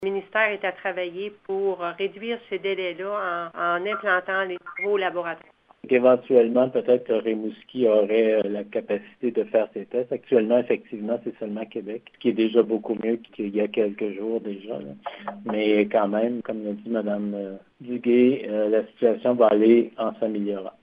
Lors du point de presse, les intervenants du CISSS de la Gaspésie ont confirmé qu’il n’y a toujours aucun cas de coronavirus  dans la région.